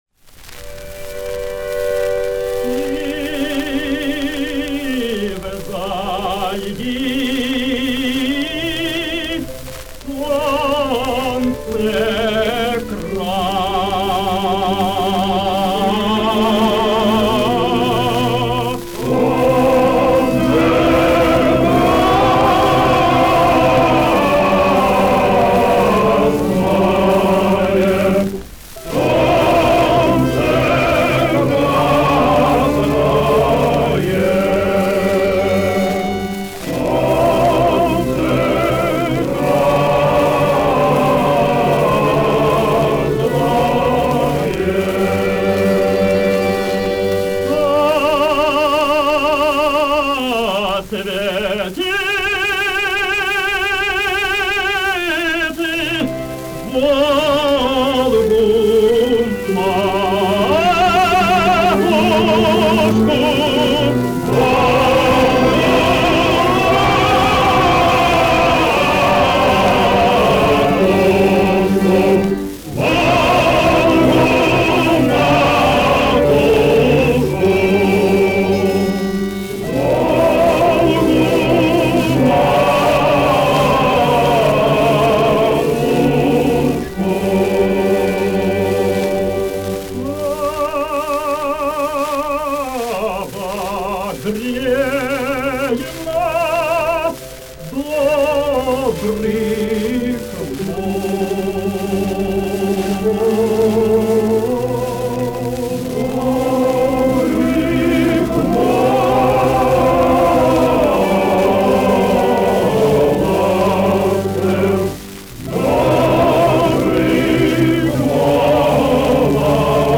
Описание: Редкая запись с блокадной пластинки.